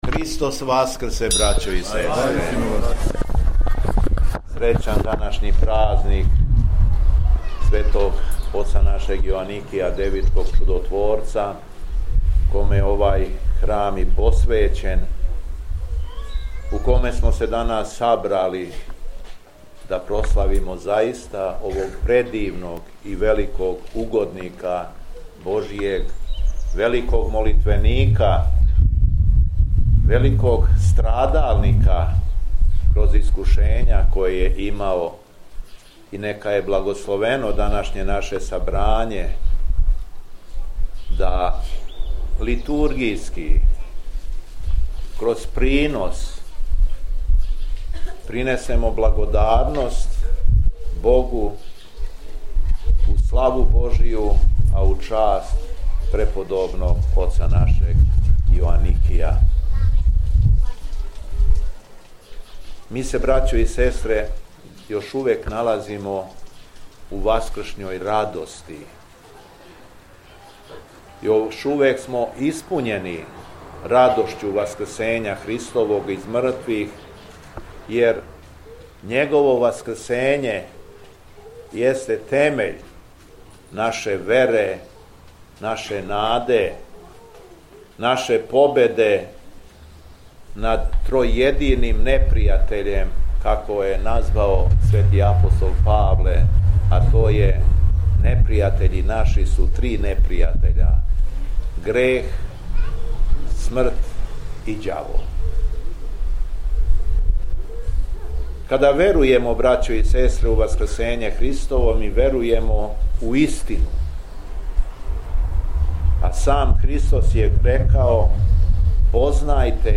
Беседа Његовог Високопреосвештенства Митрополита шумадијског г. Јована
Након прочитаног јеванђеља Митрополит се обратио сабраном народу: